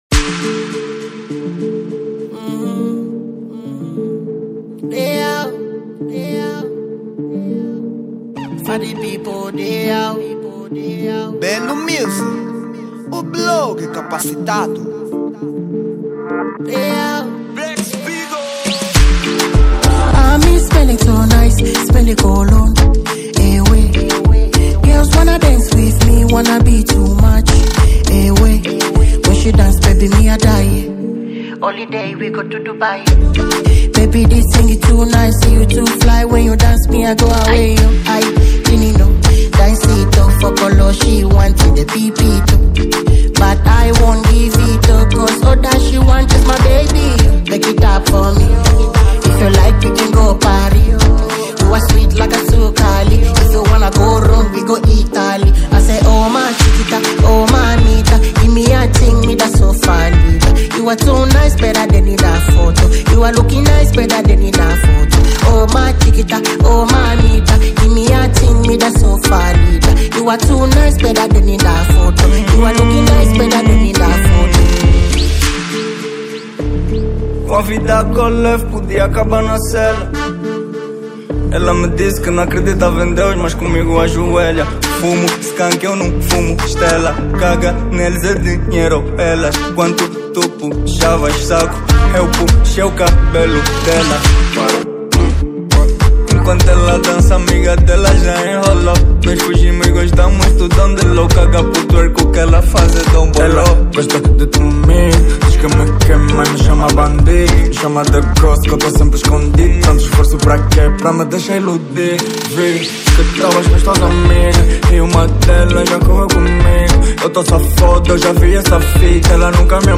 Género : Afro Vibe